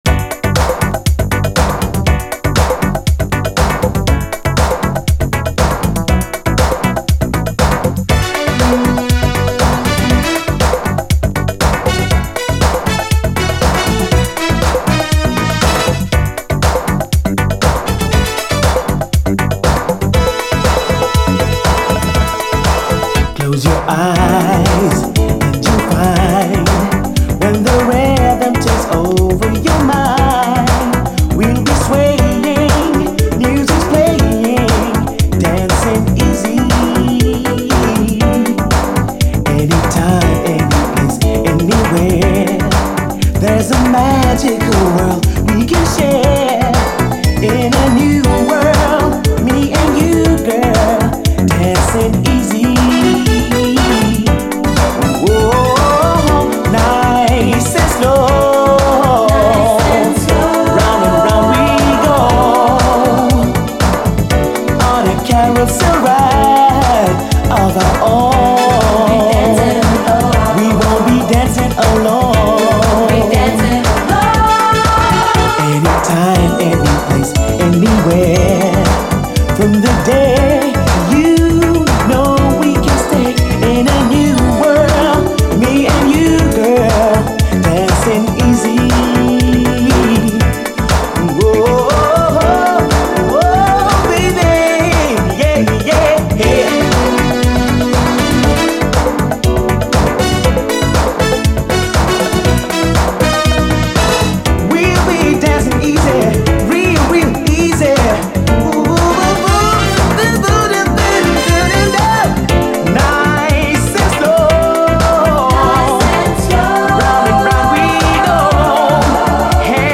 DISCO, DANCE, 7INCH
オランダ産の流麗80’Sシンセ・ブギー！